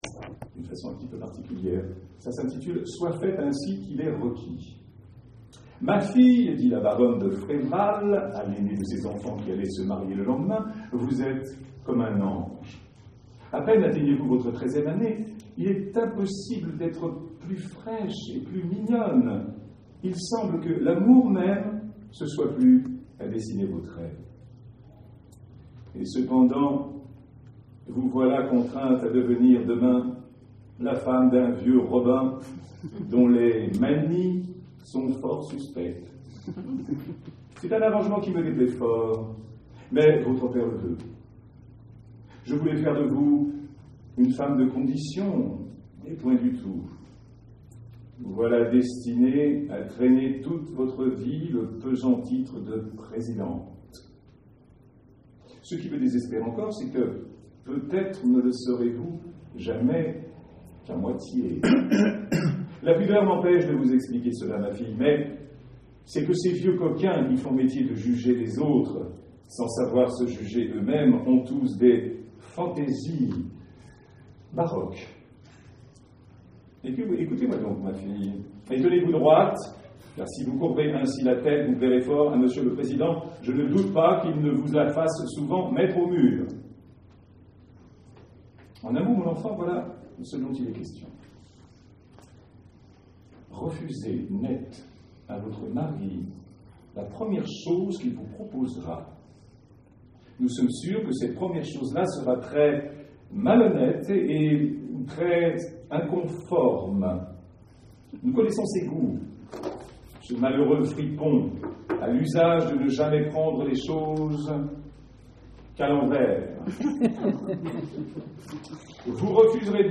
Murs-Murs et é-Cri-ts de Sade...Lecture à l'Entrepôt 2014
à l'Entrepôt, Paris